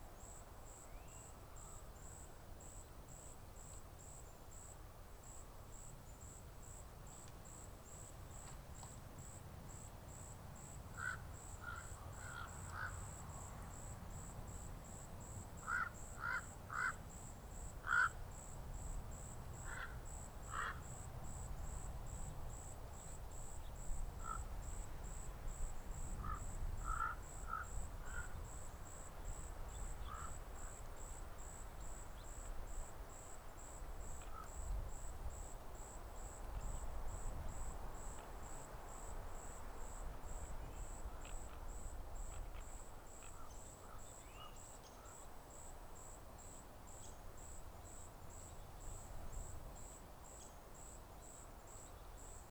Forest-Ravens.ogg